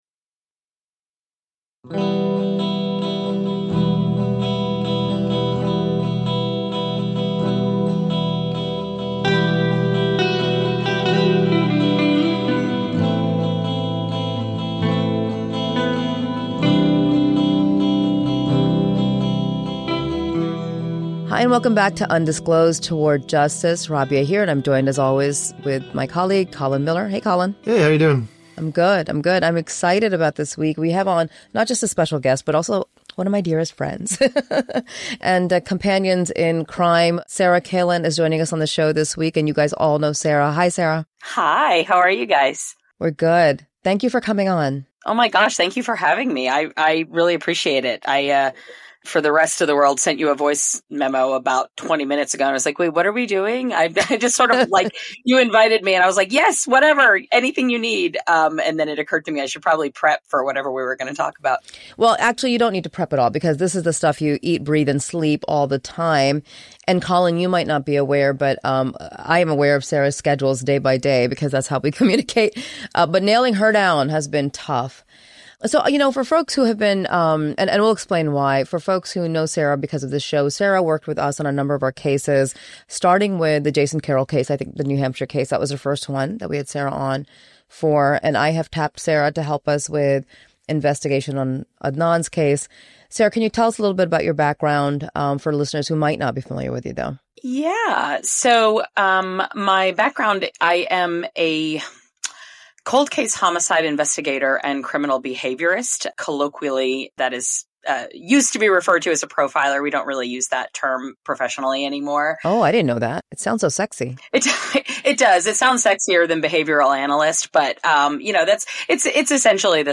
This week, we chatted with our good friend,